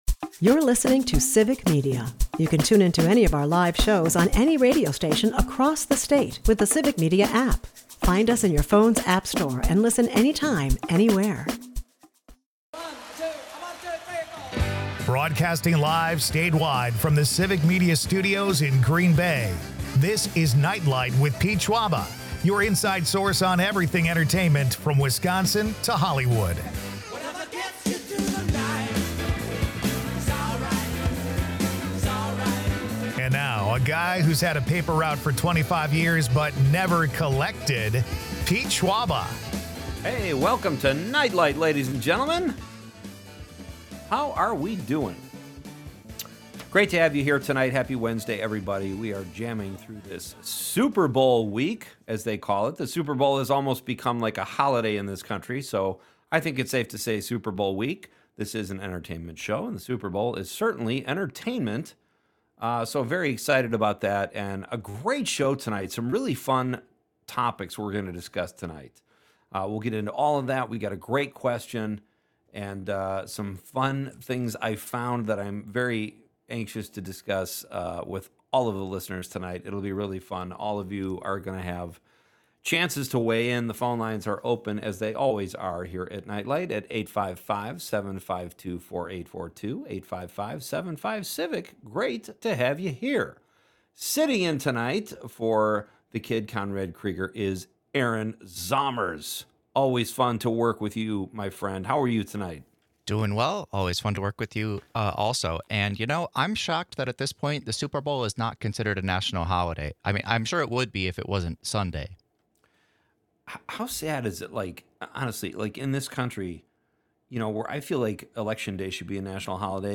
Today is the anniversary of Ace Ventura: Pet Detective's release, so we take calls and texts from listeners with some of the most memorable moments in Carrey's storied career.